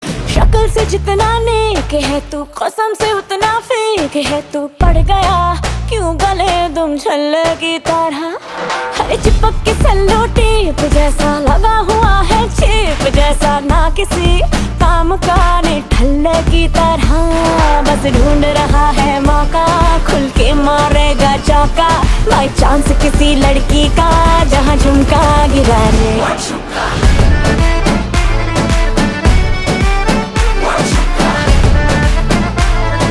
Categories: Bollywood Ringtones